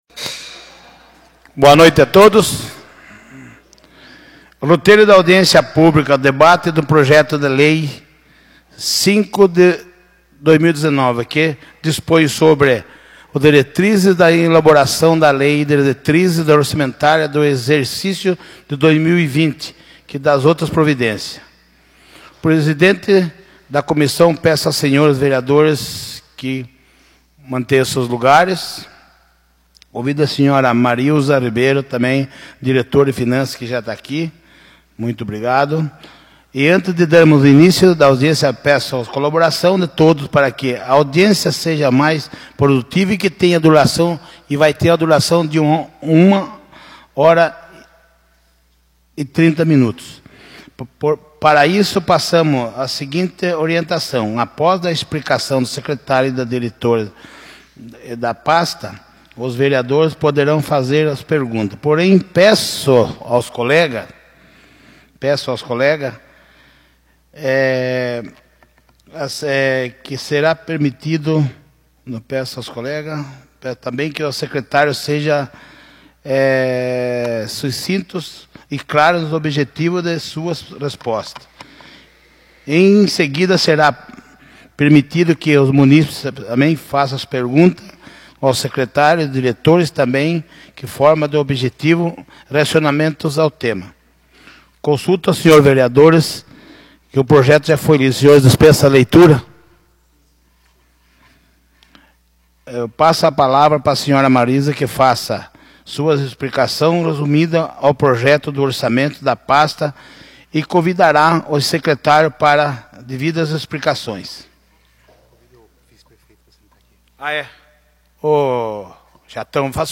Audiência Pública da LDO - Exercício de 2020